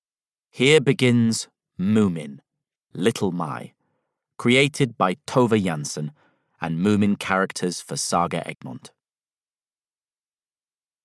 Little My (EN) audiokniha
Ukázka z knihy